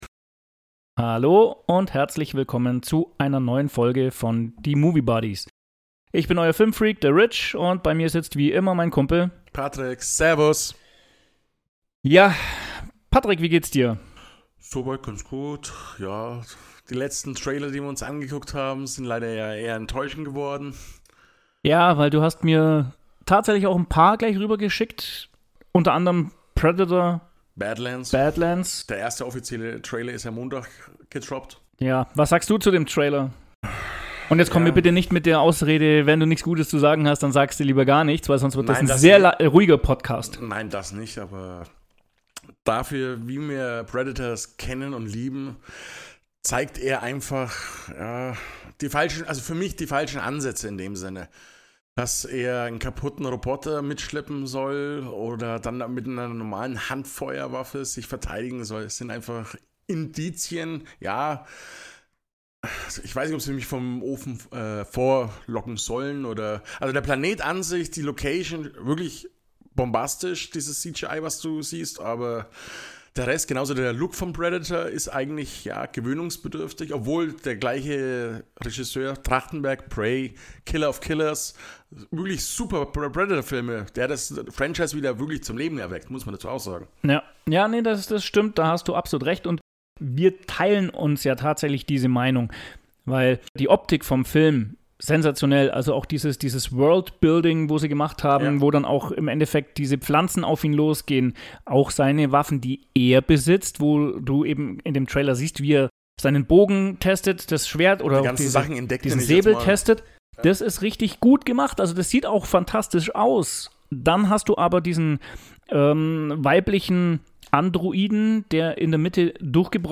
Eine abwechslungsreiche Diskussion über Veränderung, Selbstbewusstsein und Selbstwert.